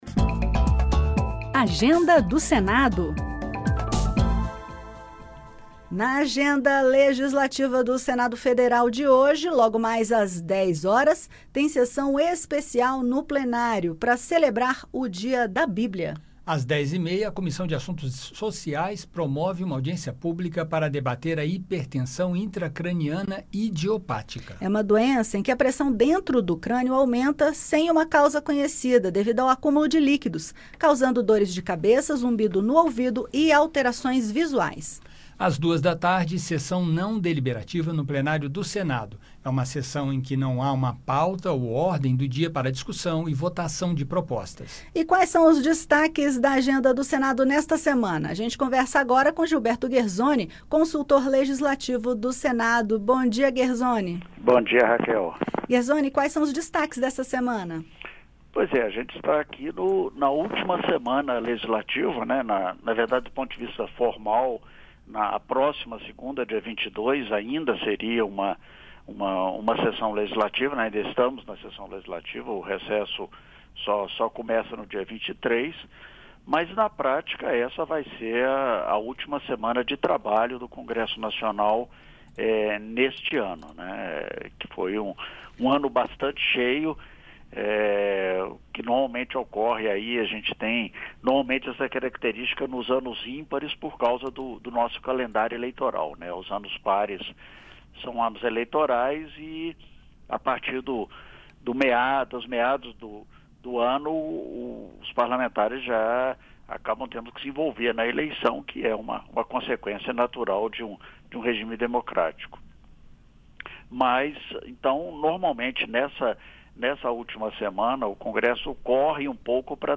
Os principais destaques da agenda do Senado ao longo da semana serão comentados pelo consultor legislativo